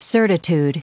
Prononciation du mot certitude en anglais (fichier audio)
Prononciation du mot : certitude